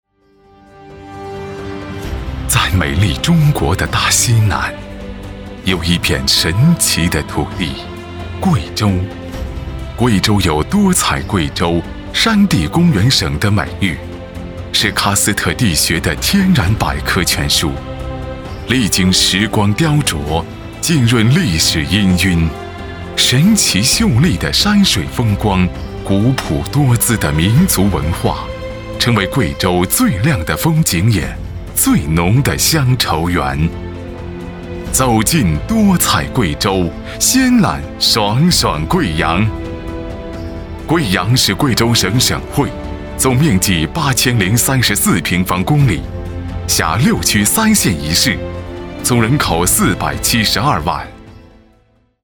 男国294_宣传片_城市_贵州贵阳_浑厚.mp3